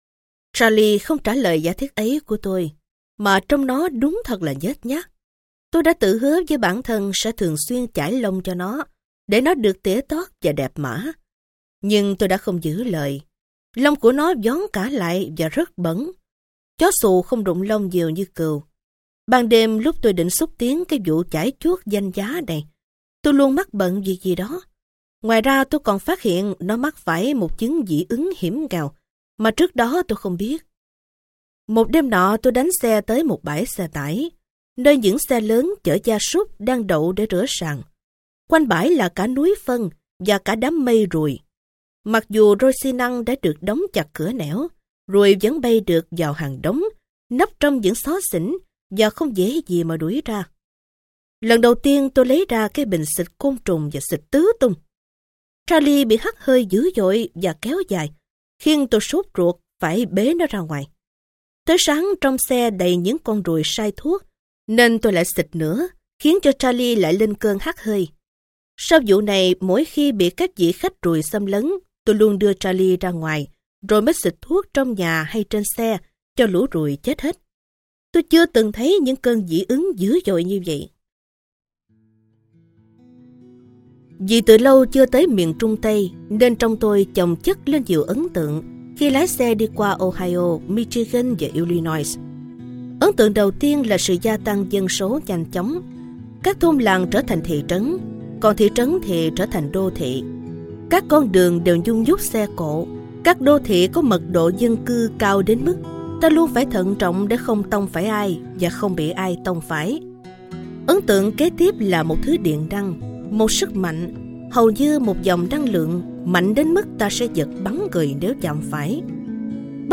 Sách nói Tôi, Charley Và Hành Trình Nước Mỹ - John Steinbeck - Sách Nói Online Hay